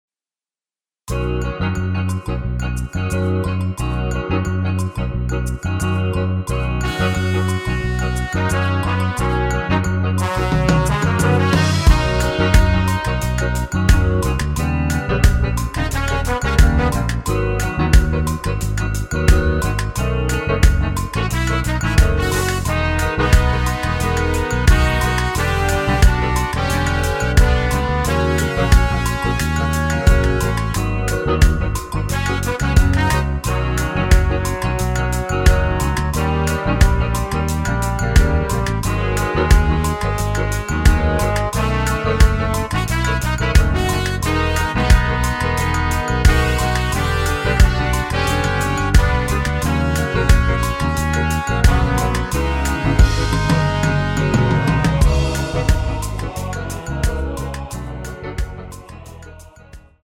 원키에서(-5)내린 MR입니다.
엔딩이 길어 축가에 사용 하시기 좋게 엔딩을 짧게 편곡 하였습니다.(원키 코러스 버전 미리듣기 참조)
◈ 곡명 옆 (-1)은 반음 내림, (+1)은 반음 올림 입니다.
앞부분30초, 뒷부분30초씩 편집해서 올려 드리고 있습니다.
중간에 음이 끈어지고 다시 나오는 이유는